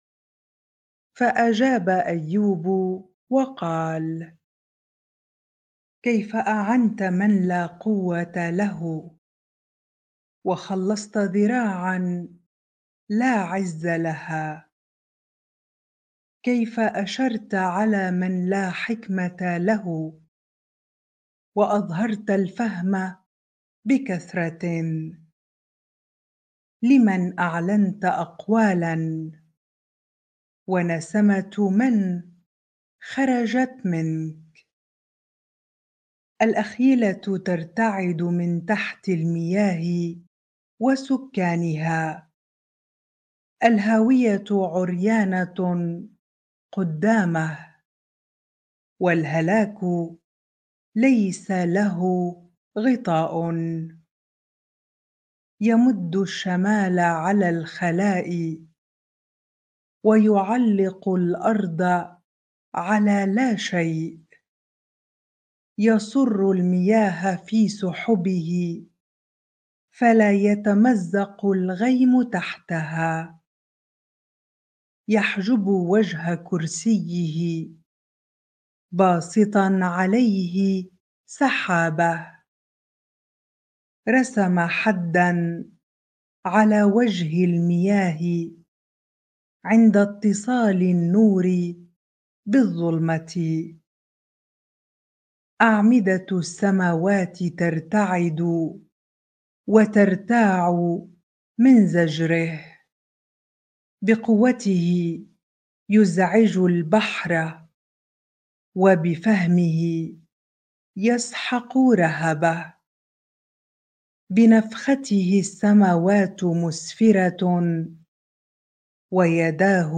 bible-reading-Job 26 ar